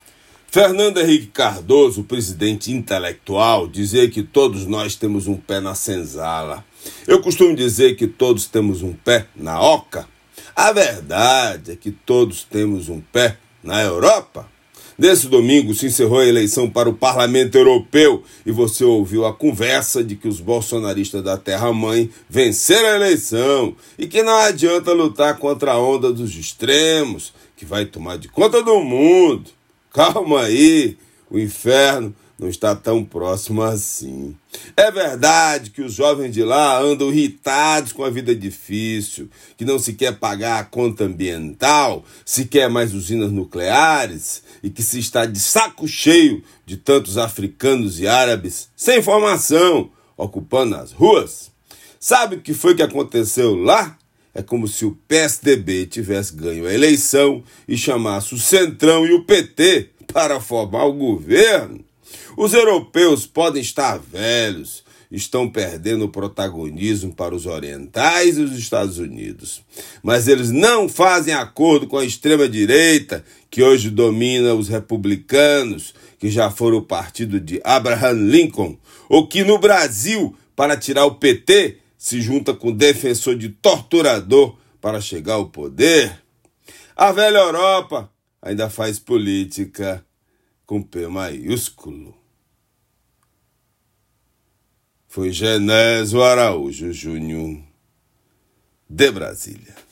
Comentário desta terça-feira